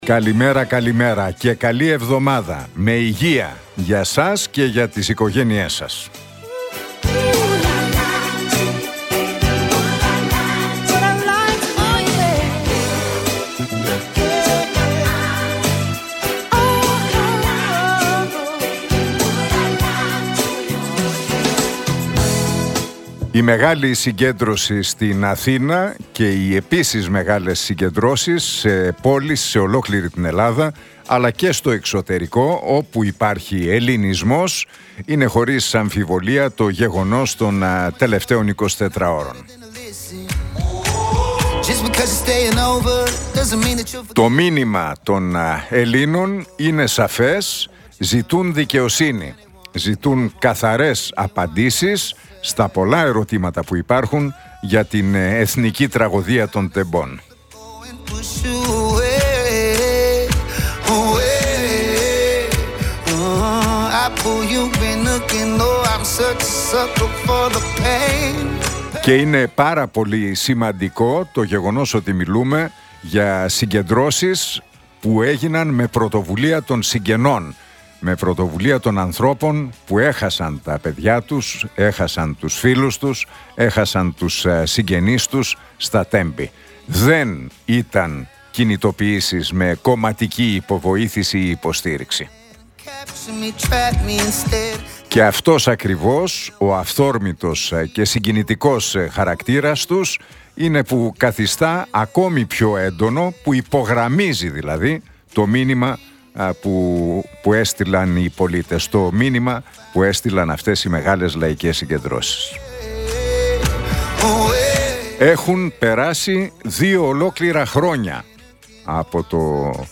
Ακούστε το σχόλιο του Νίκου Χατζηνικολάου στον ραδιοφωνικό σταθμό RealFm 97,8, την Δευτέρα 27 Ιανουαρίου 2025.